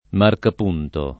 vai all'elenco alfabetico delle voci ingrandisci il carattere 100% rimpicciolisci il carattere stampa invia tramite posta elettronica codividi su Facebook marcapunto [ markap 2 nto ] o marcapunti [ markap 2 nti ] s. m.; pl.